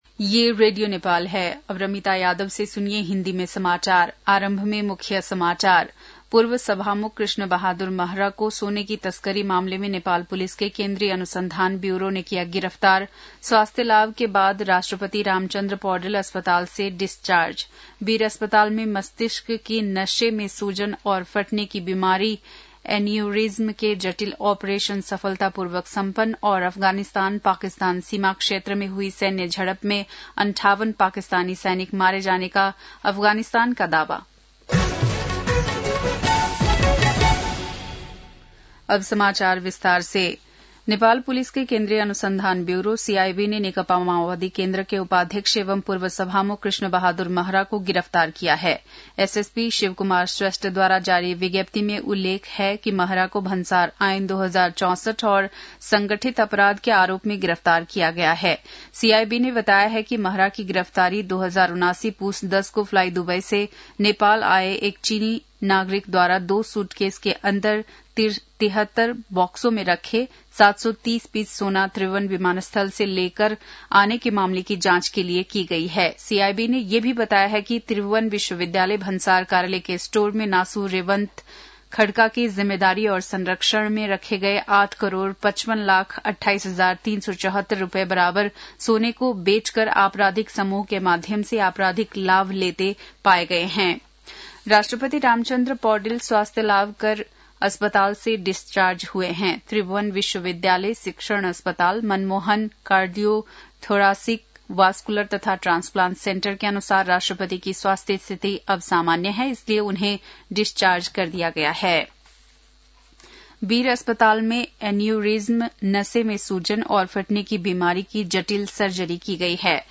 बेलुकी १० बजेको हिन्दी समाचार : २६ असोज , २०८२
10-pm-hindi-news-6-26.mp3